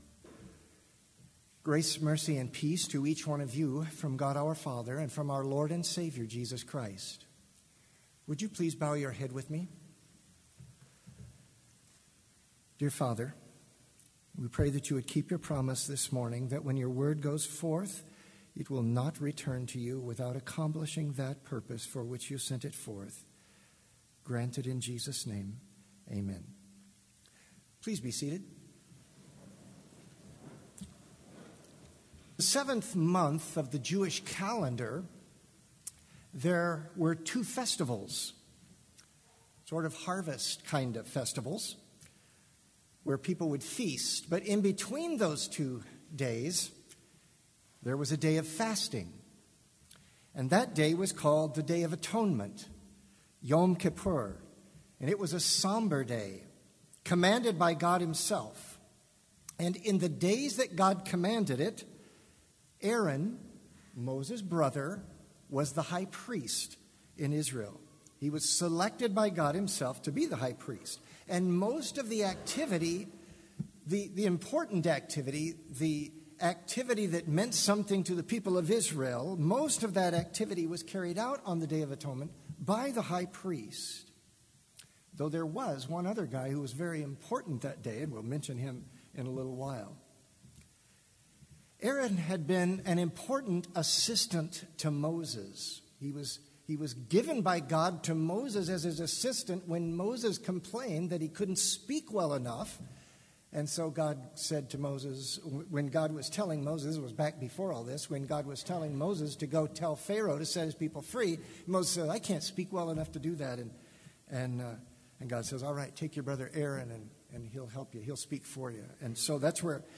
Complete service audio for Chapel - March 5, 2019
Sermon Only
Hymn 239 - I Lay My Sins On Jesus